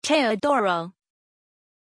Pronuncia di Teodoro
pronunciation-teodoro-zh.mp3